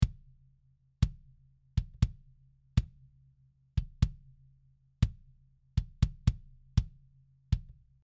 例として、ドラムのバスドラムのデータを使用します。